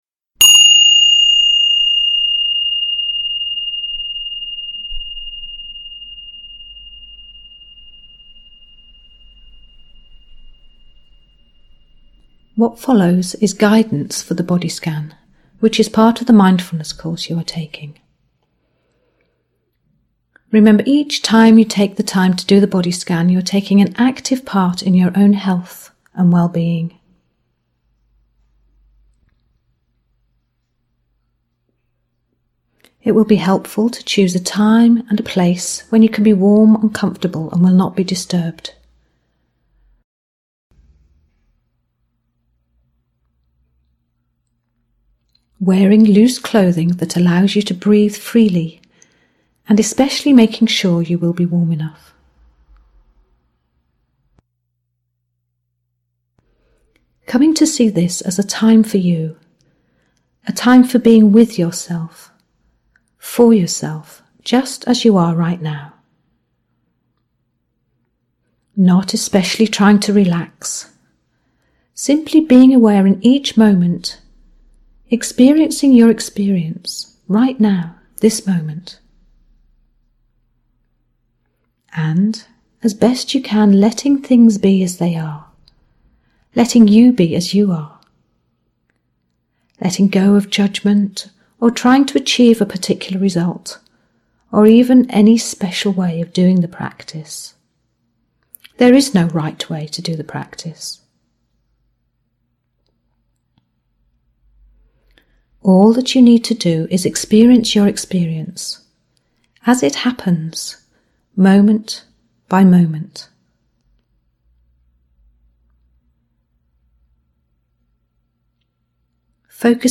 The following body scan practices are approx. 45 minutes long: